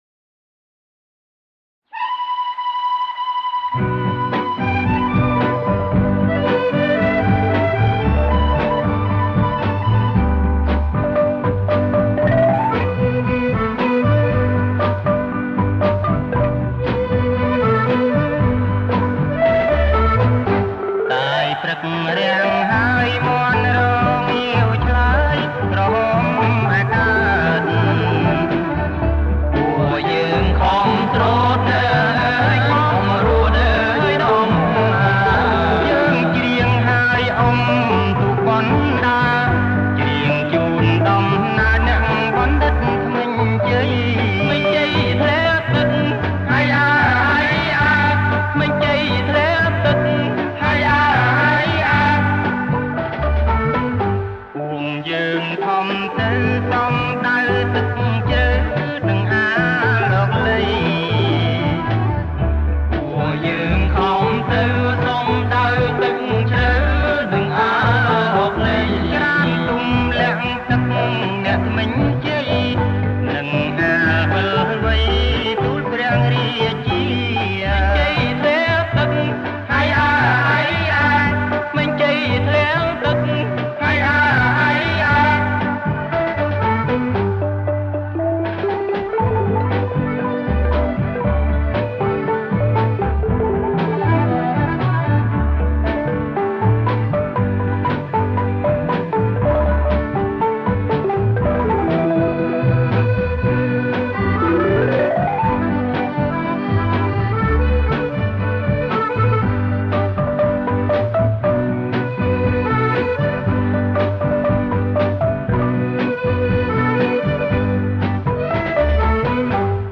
ថតផ្ទាល់ពីcassette